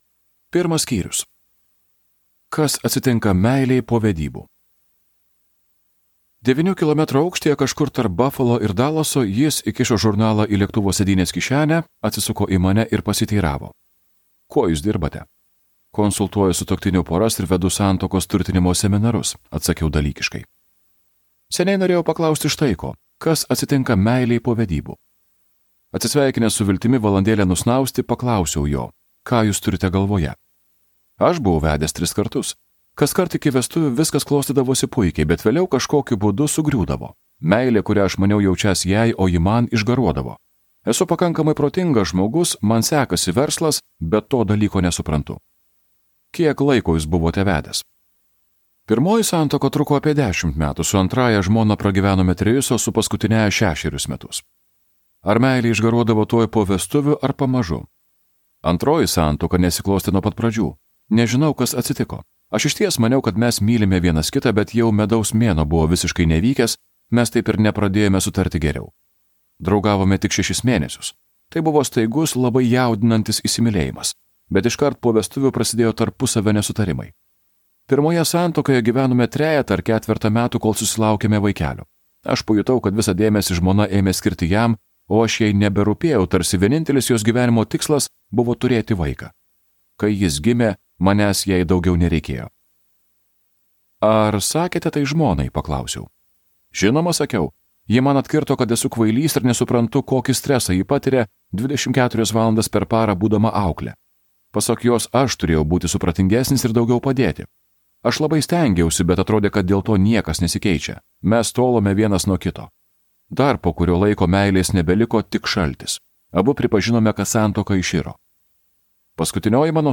Skaityti ištrauką play 00:00 Share on Facebook Share on Twitter Share on Pinterest Audio Penkios meilės kalbos.